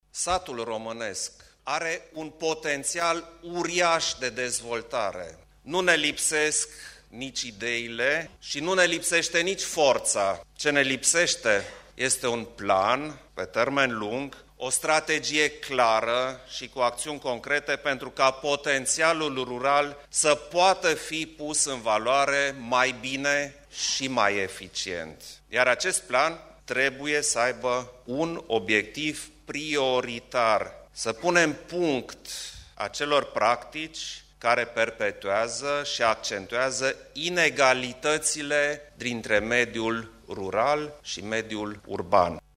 Programul Naţional de Dezvoltare Locală trebuie regândit, nu desfiinţat, a subliniat preşedintele Klaus Iohannis, azi, la Adunarea Generală a Asociaţiei Comunelor din România.
Şeful statului le-a vorbit edililor prezenţi la acest eveniment despre importanţa investiţiilor eficiente pentru dezvoltarea comunităţilor locale.